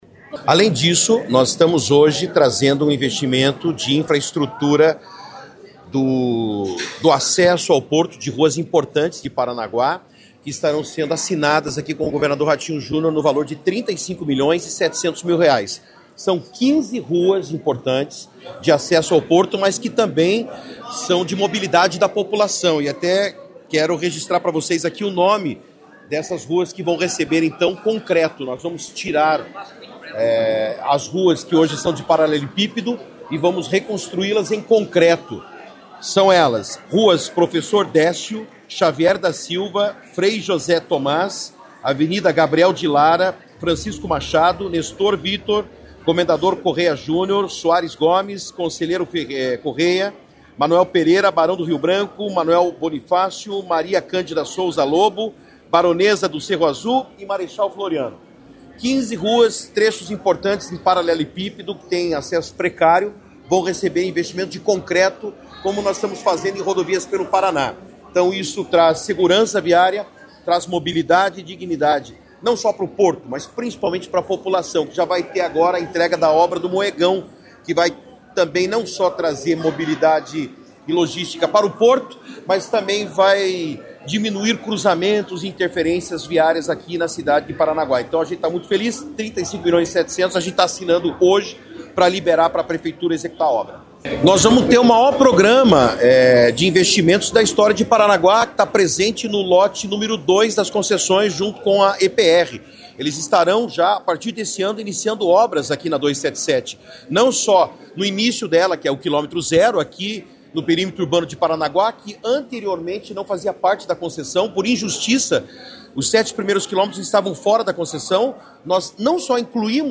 Sonora do secretário Estadual da Infraestrutura e Logística, Sandro Alex, sobre a pavimentação em concreto de ruas ao redor do Porto de Paranaguá